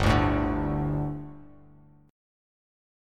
Gsus2 chord